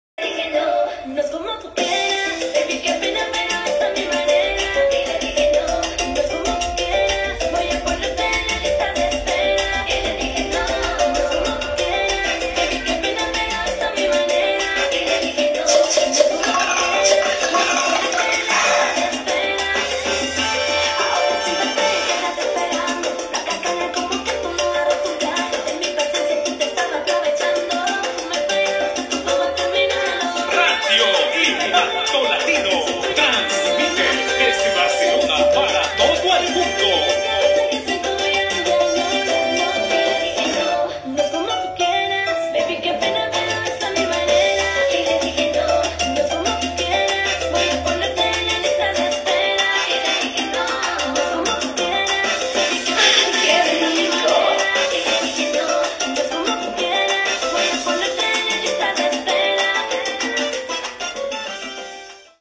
Tema musical i identificació de l'emissora